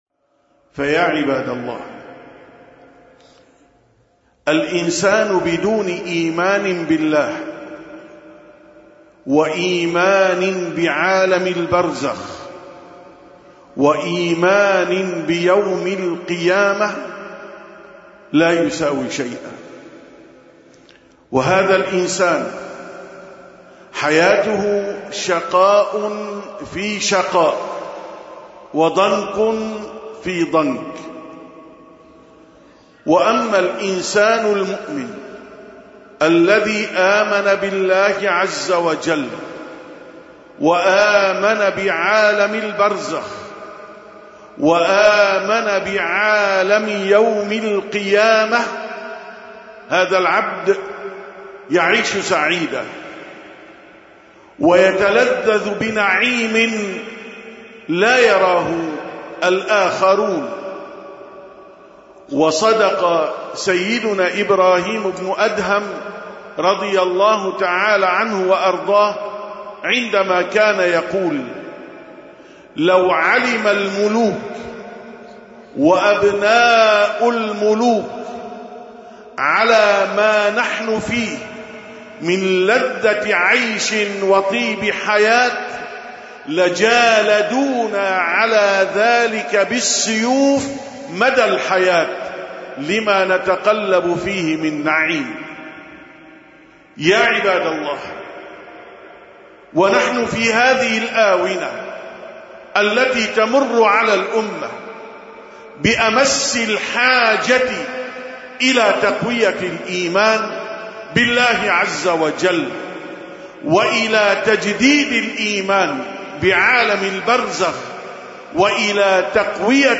853ـ خطبة الجمعة: لذة الإيمان